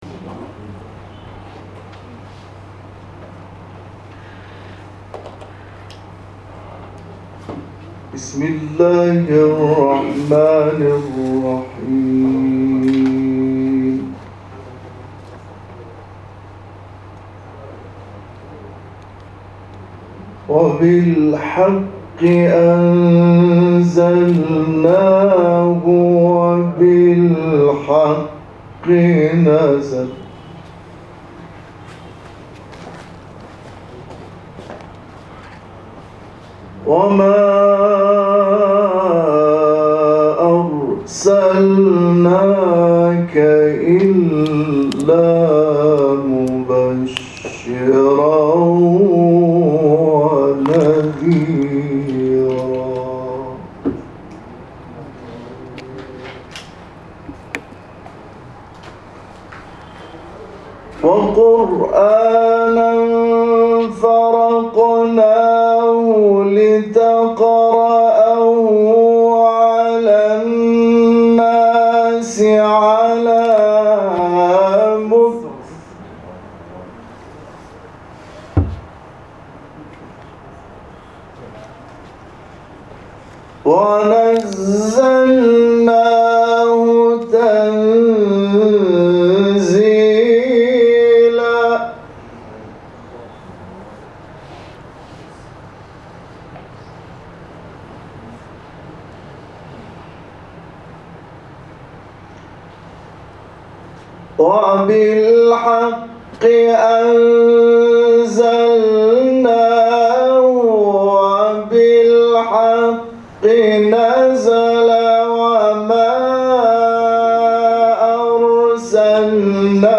قاری بین‌المللی کشورمان، آیاتی از سوره اسراء را تلاوت کرد.